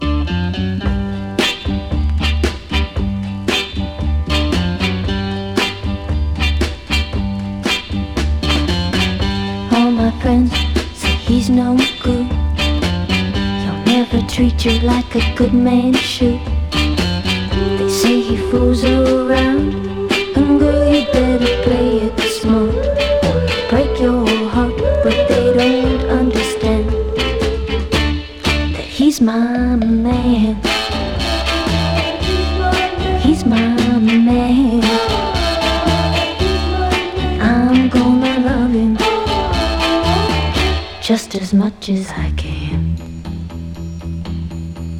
Rock, Pop, Vocal　Australia　12inchレコード　33rpm　Mono